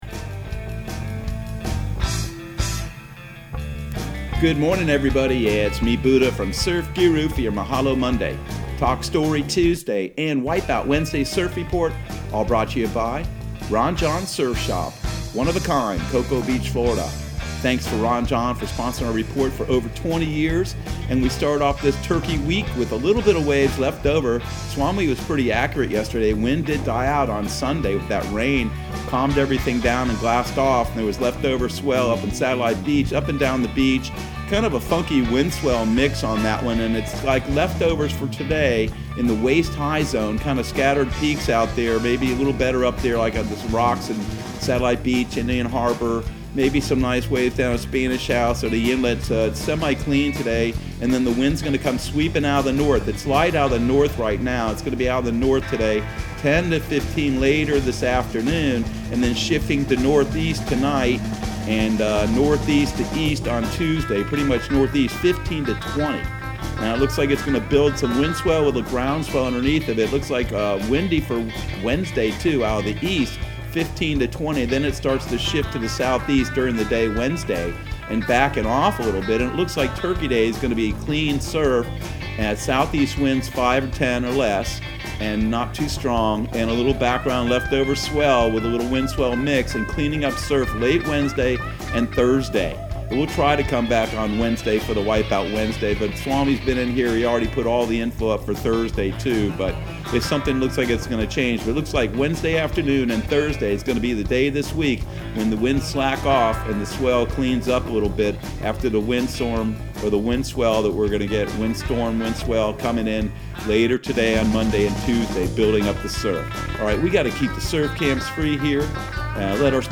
Surf Guru Surf Report and Forecast 11/23/2020 Audio surf report and surf forecast on November 23 for Central Florida and the Southeast.